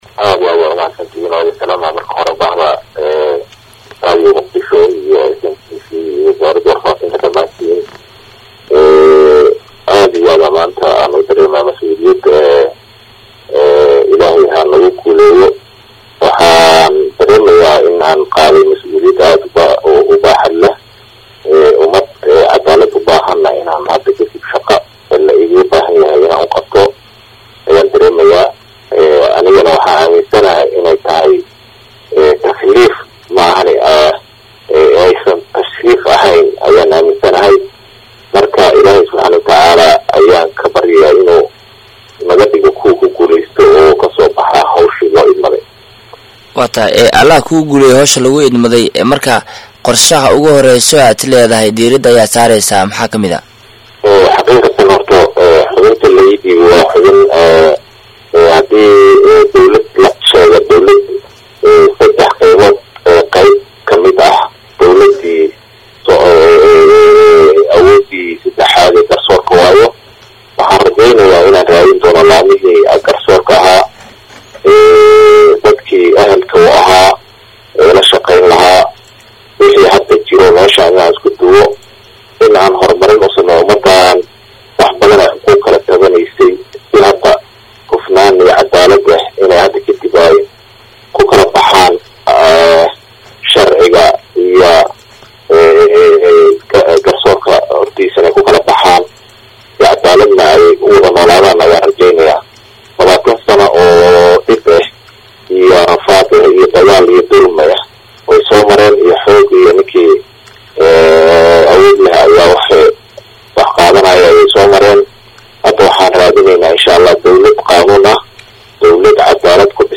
Mudane Cabdiqaadir Cismaan Cabdisalaan Kheyraat oo wareysi gaar ah siiyay Radio Muqdisho ayaa xusay in uu ku dadaali doono sidii bulshada ay u heli lahaayeen adeeg garsoor oo cadaalad ku dhisan.